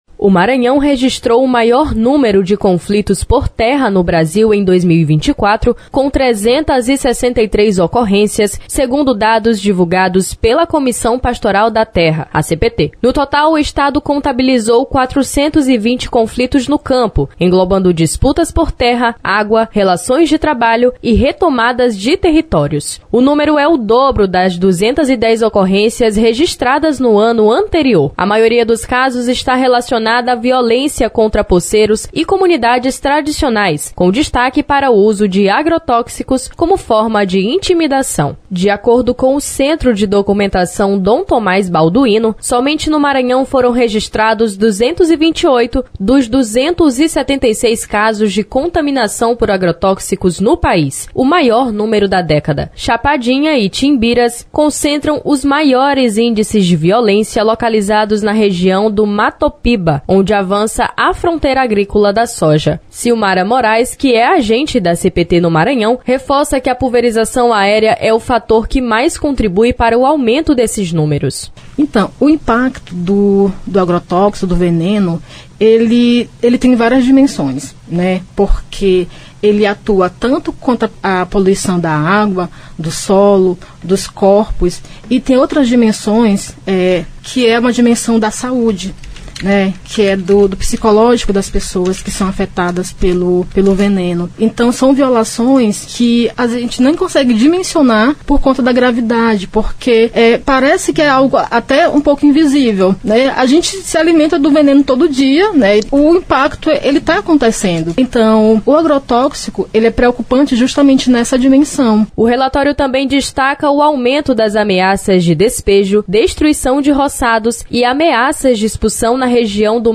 Dados da Comissão Pastoral da Terra (CPT), apontam que o estado registrou 420 conflitos no campo, o dobro do ano anterior, com destaque para a violência contra comunidades tradicionais; pulverização aérea de veneno é apontada como principal ameaça. A reportagem